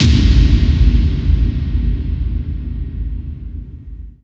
VEC3 FX Reverbkicks 10.wav